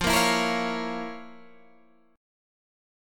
FM7sus4#5 chord